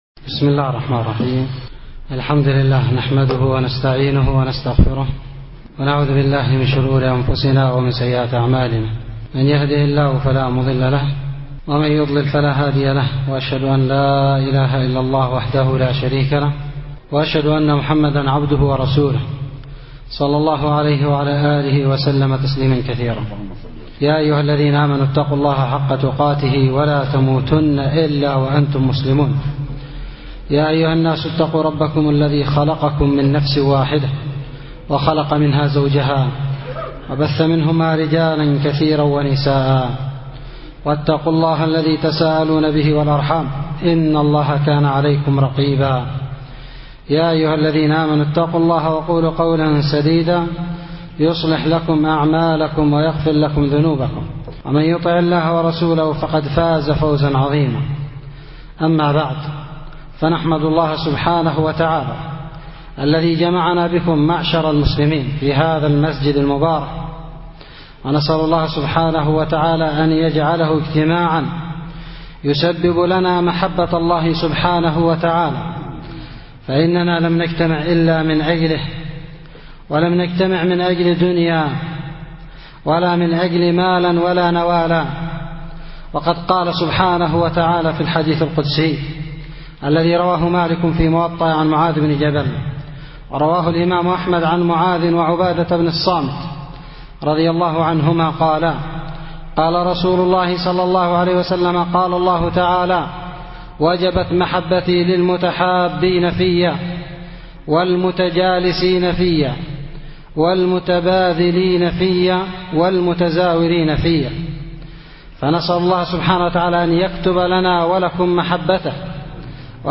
محاضره